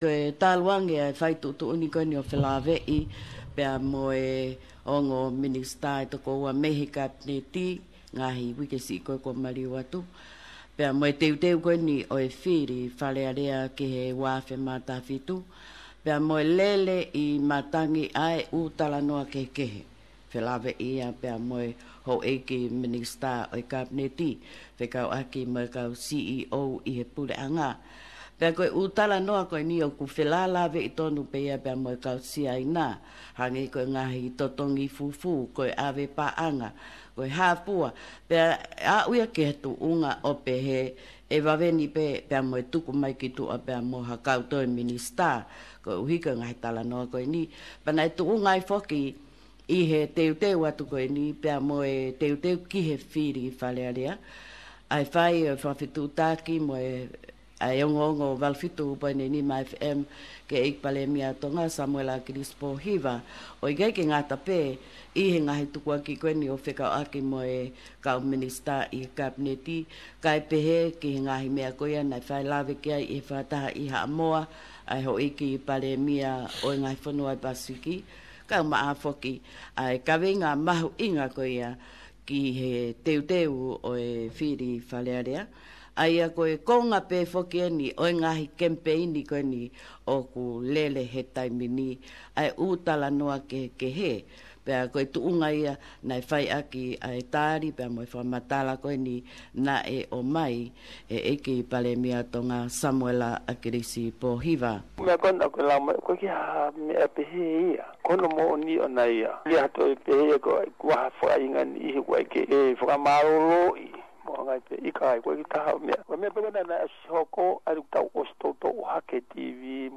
Special Interview of the Tongan PM, 'Akilisi Pohiva regarding current issues and the upcoming Election in November.